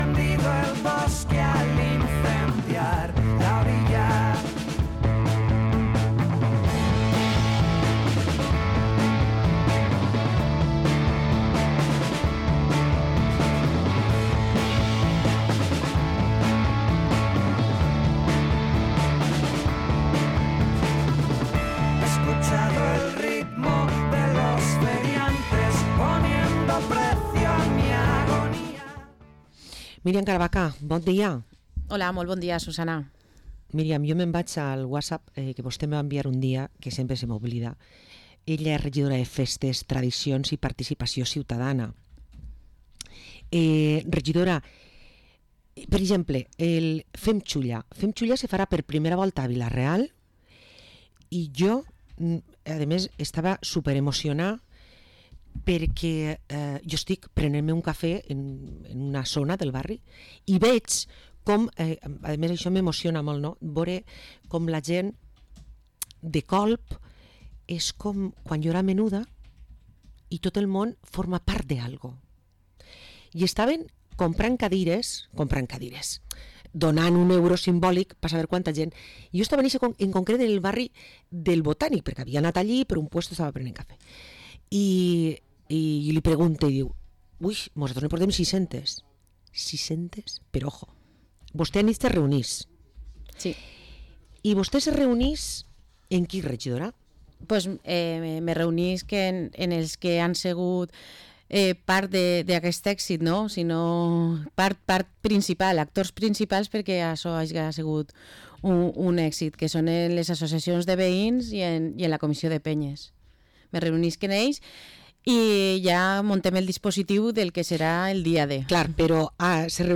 «Fem Xulla», ens ho explica la regidora Miriam Caravaca